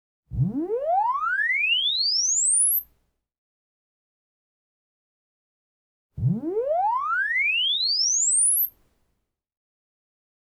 Käytin sarjan siniaalto-sweepejä MS-moduulin sivumikrofonin asetuksien havainnollistamiseen. Mitä pienempi sivumikin taso on, sitä kapeammaksi stereokuva muuttuu.
Side-mic -2 dB: